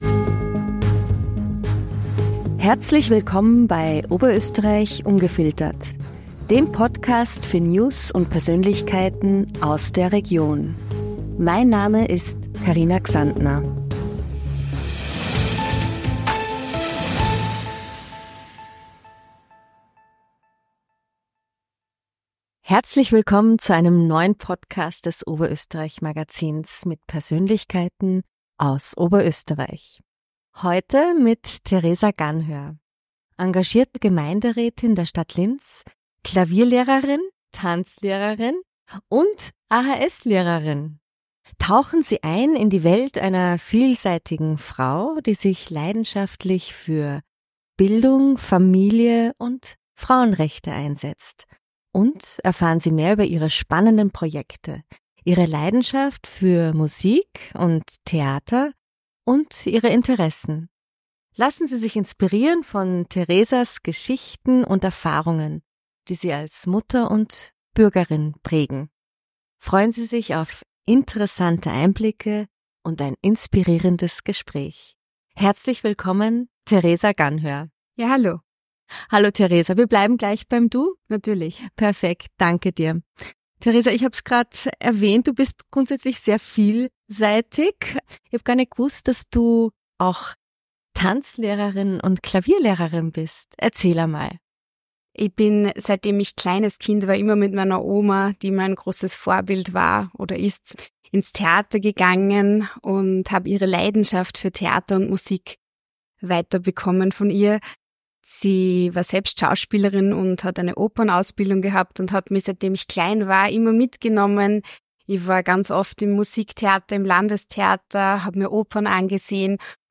Beschreibung vor 1 Jahr Diesmal begrüßen wir Theresa Ganhör, eine beeindruckend vielseitige Persönlichkeit und engagierte Gemeinderätin der Stadt Linz. Sie teilt spannende Einblicke in ihre Leidenschaften für Musik und Theater, die sie schon von klein auf durch ihre Großmutter inspiriert haben.
Abgerundet wird das Gespräch mit persönlichen Einblicken in Theresas Freizeitaktivitäten, ihre Reiseleidenschaft und ihre inspirierenden Werte wie Leidenschaft, Wertschätzung und Liebe.